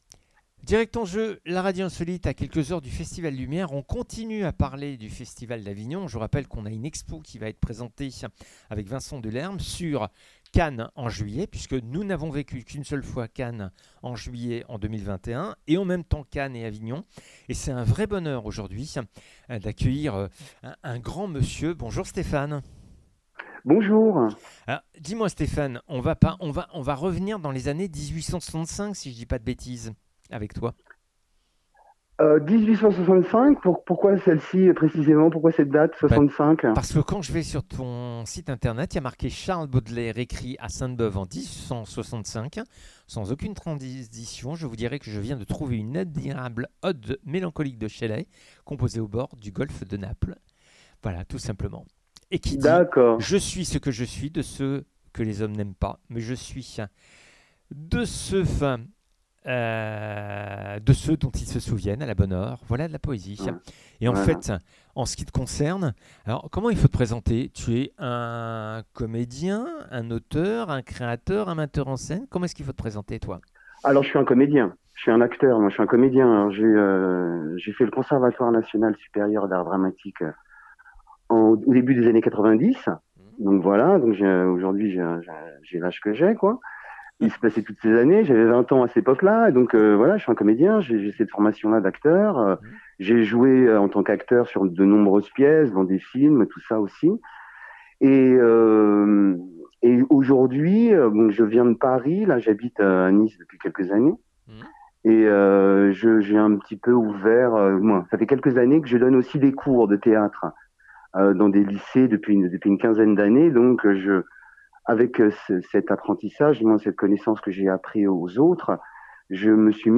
HYPOCRITES LECTEURS est un spectacle théâtral.
C'est une sorte de "conférence".